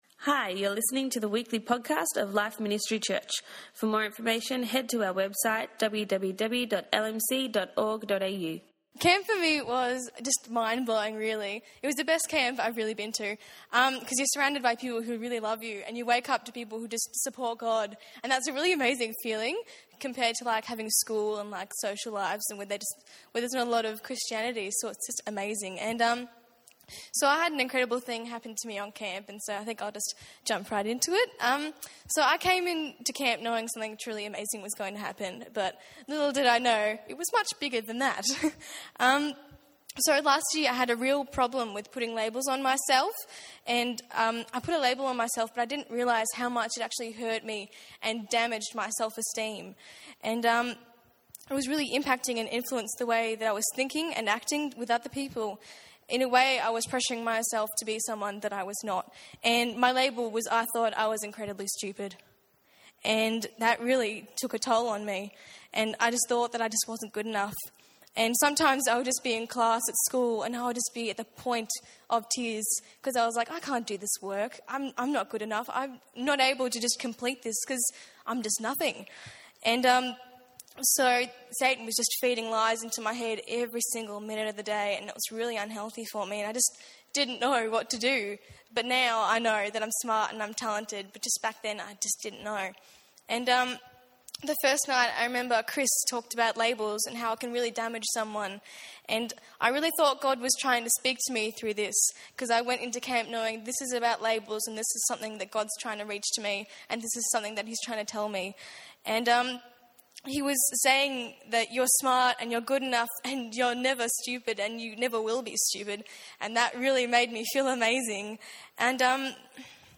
Summer Camp Testimony Night
On Sunday night we heard some of the testimonies from people who attended camp. The stories of the encounters they had with God are an encouragement to all!